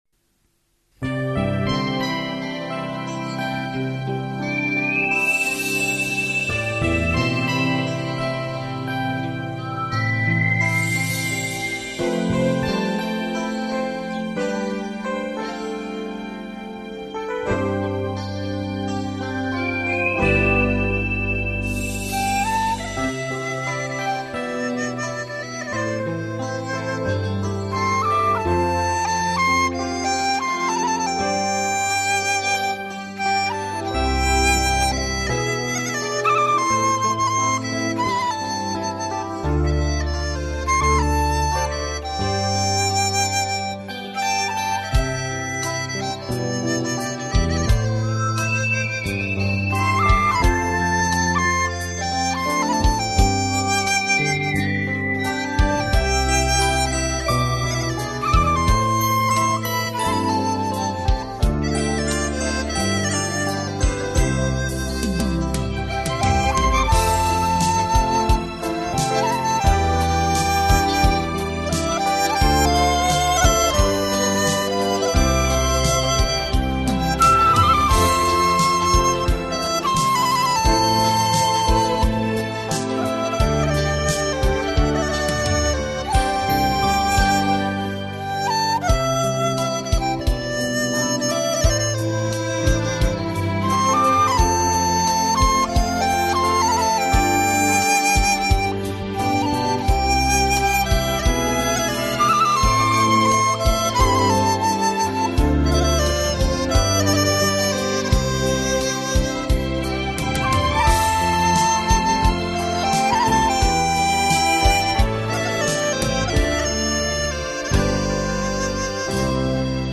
【笛子专辑】
笛子是吹奏乐器。
为民间最常用的乐器之一。